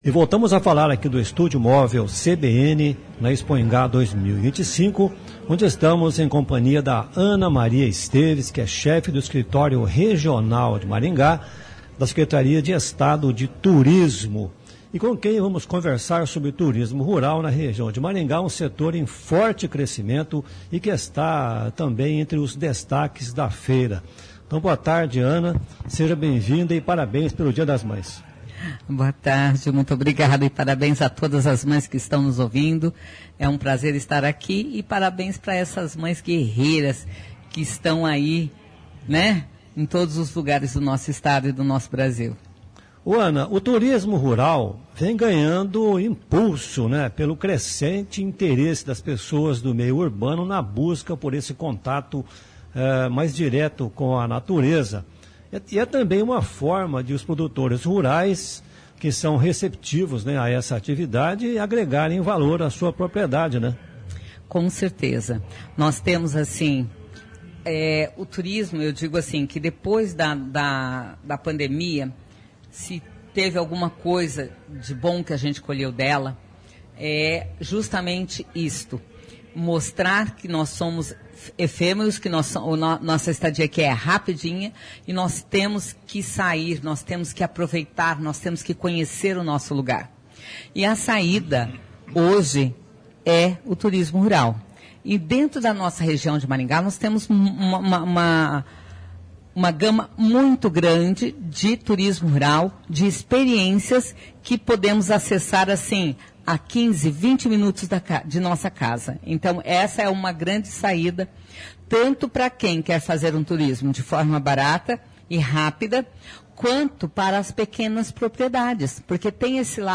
Expoingá 2025